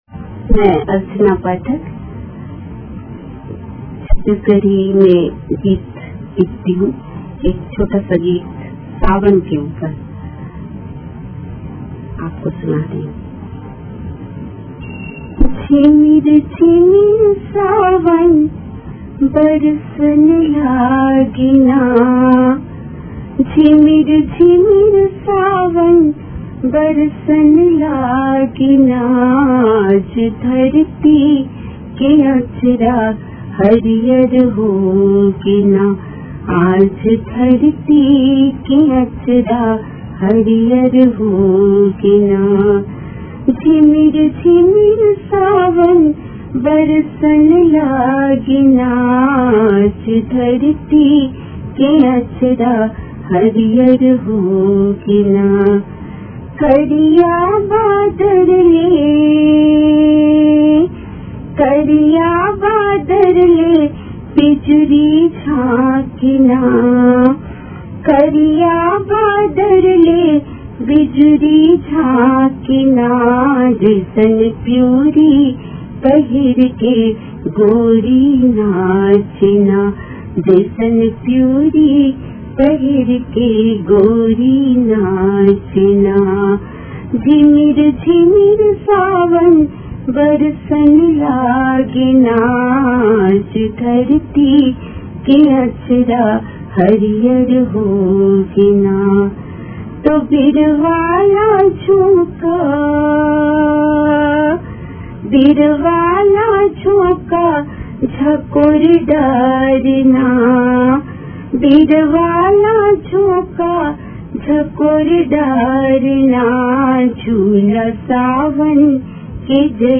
छत्तीसगढ़ी गीत